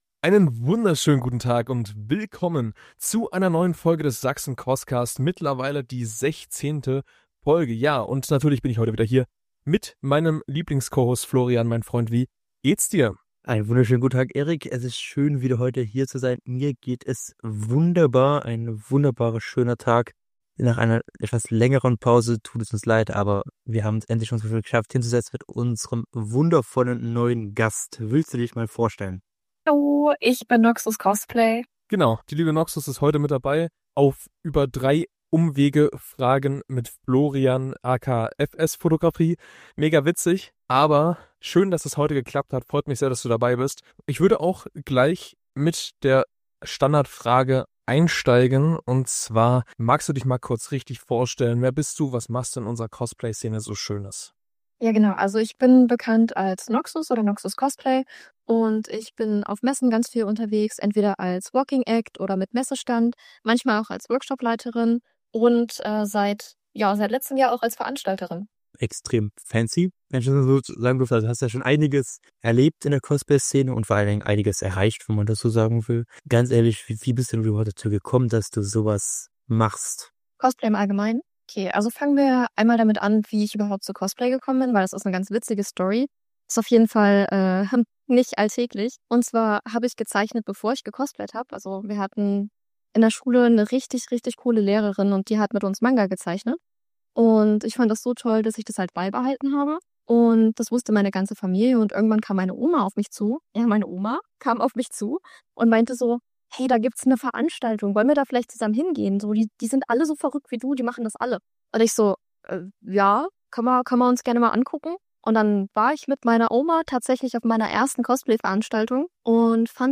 In der 16. Folge des Sachsen-CosCast sprechen wir mit unserem Gast